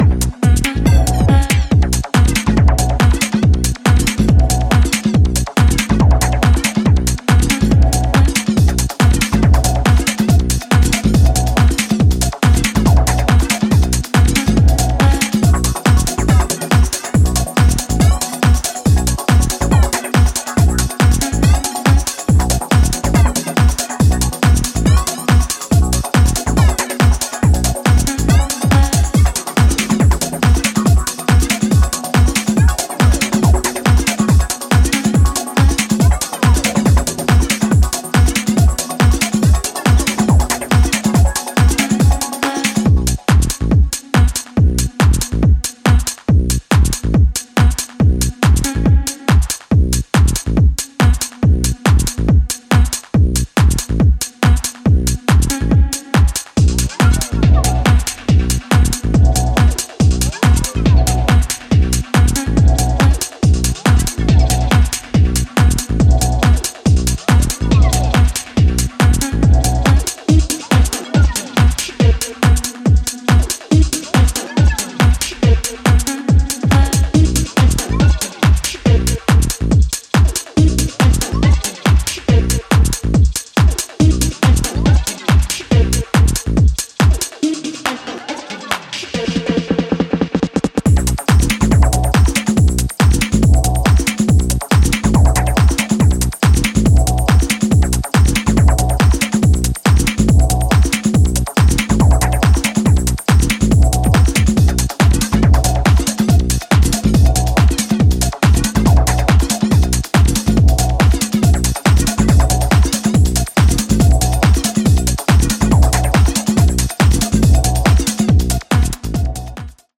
全編非常に歯切れの良いハイテンポのグルーヴが走るモダン・テック・ハウスで痛快そのもの。